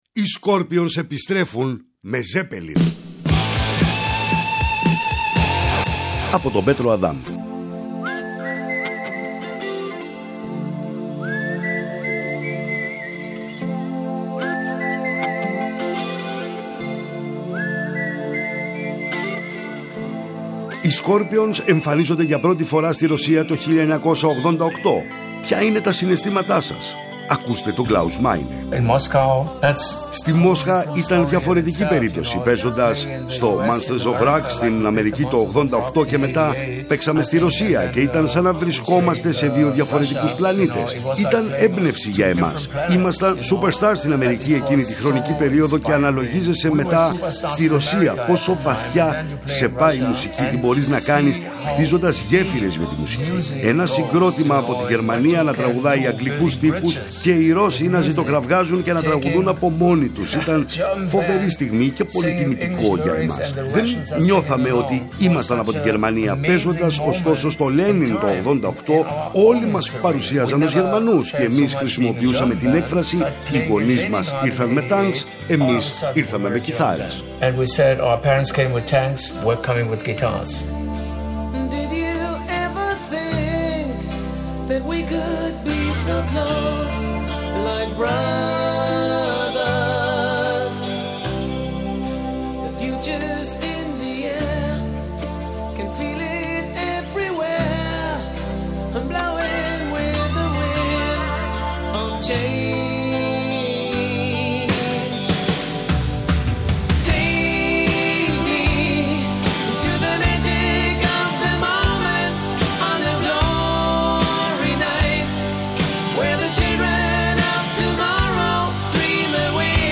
Ακούστε τον Klaus Meine: